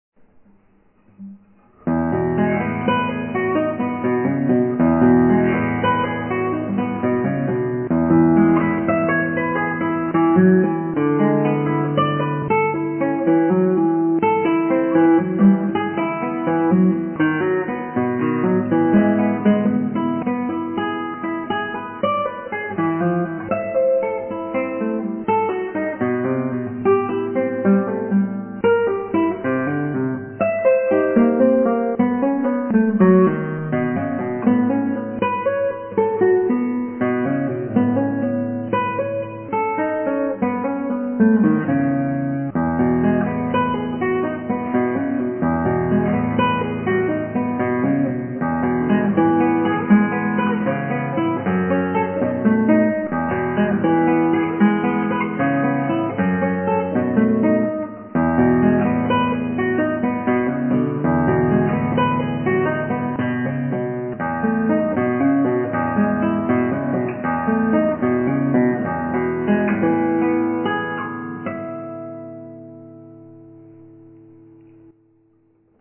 アルカンヘルで